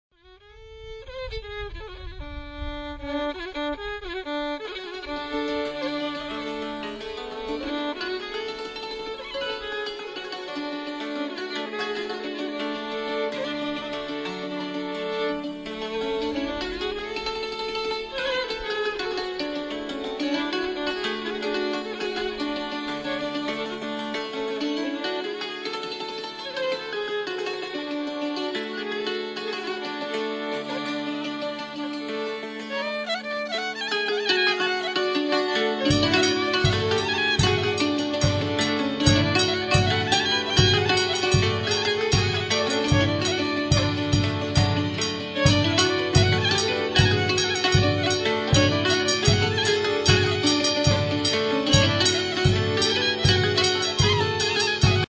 Authentic Hutsul Music From the Ukrainian Carpathians